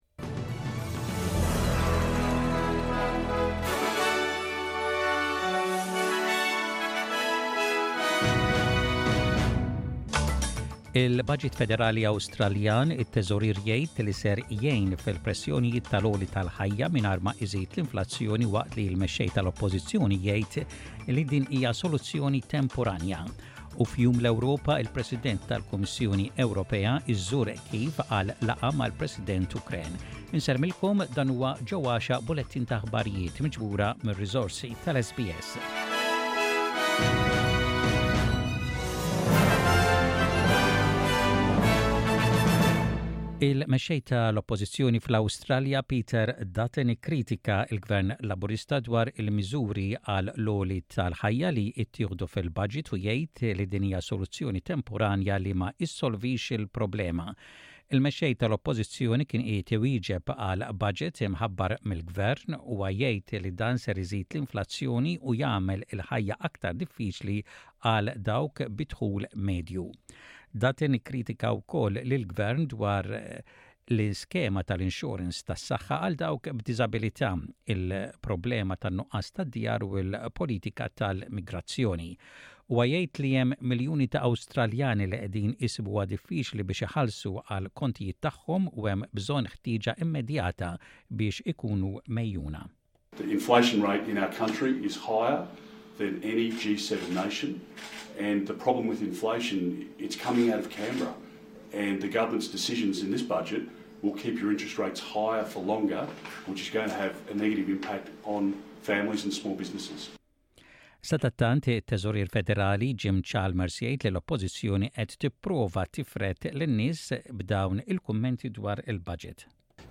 SBS Radio | Maltese News: 12/05/23